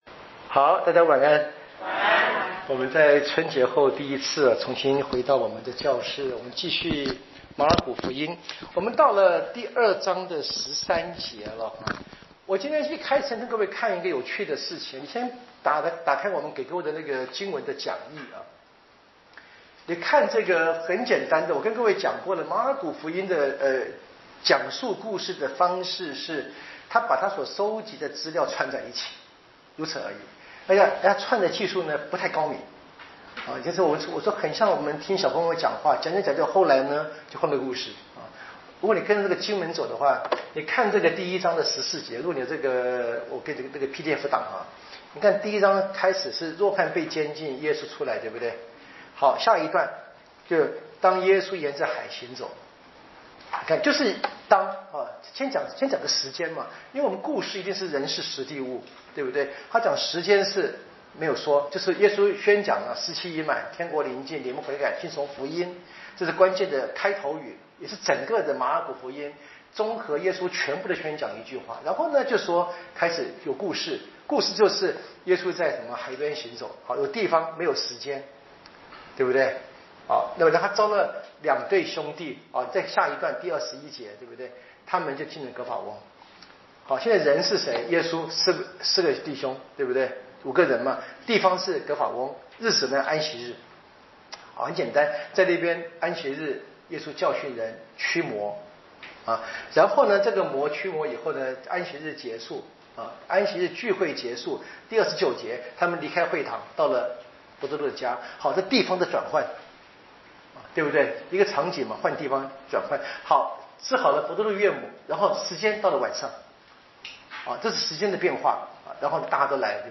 【圣经讲座】《马尔谷福音》